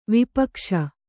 ♪ vipakṣa